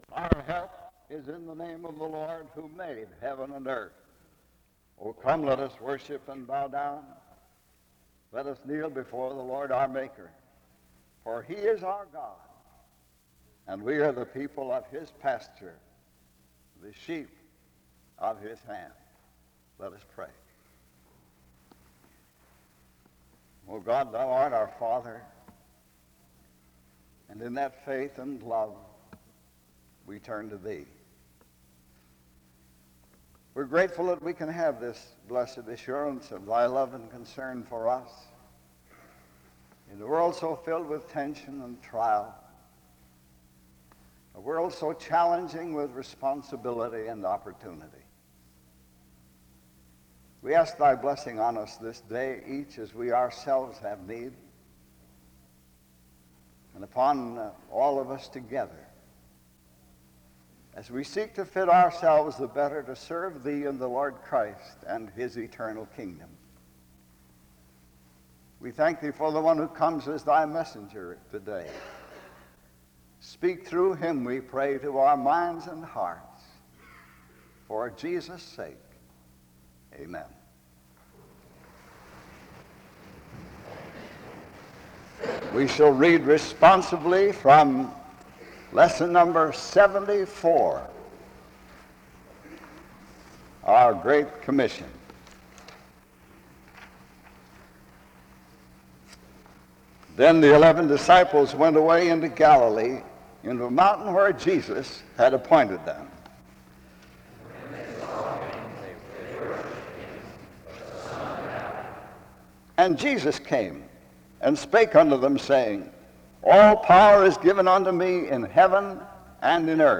The service begins with a prayer (0:00-1:20). The speaker then reads a responsive reading (1:21-3:24).